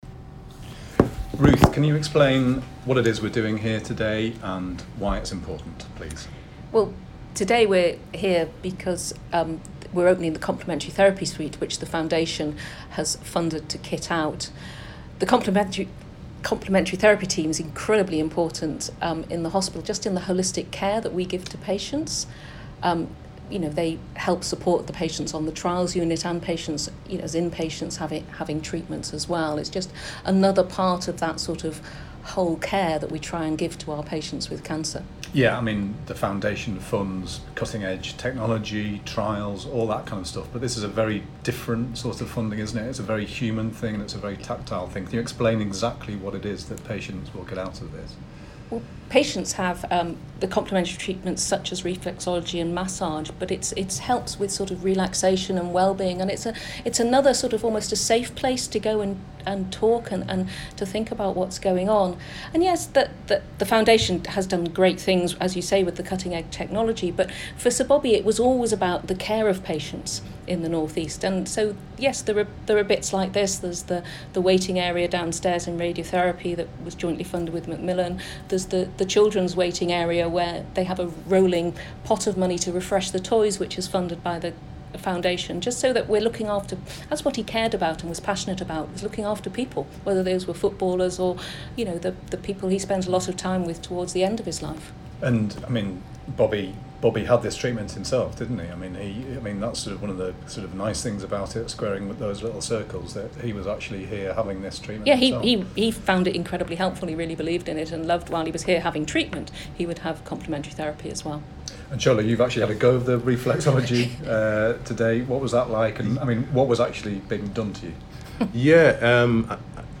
at the opening of the new Complementary Therapy Suite at the Northern Centre for Cancer Care